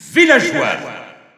Announcer pronouncing male Villager in French PAL.
Villager_French_PAL_Announcer_SSBU.wav